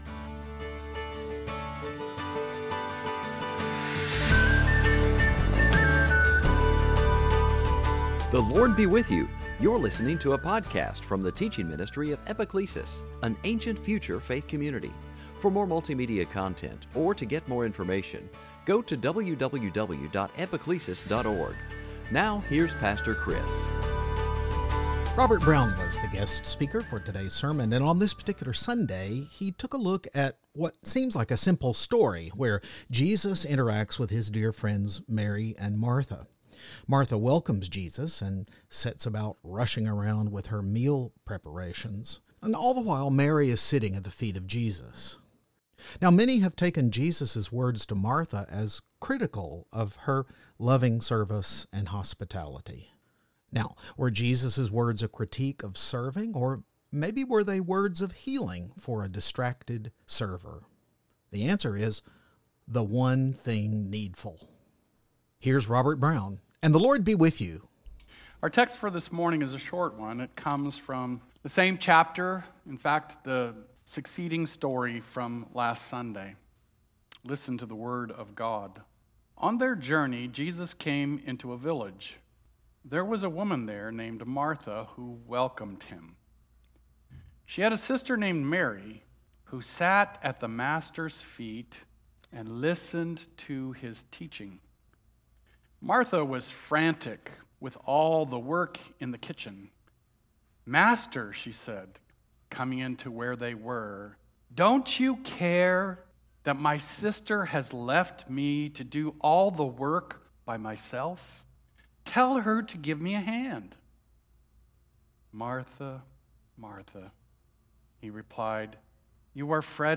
Series: Sunday Teaching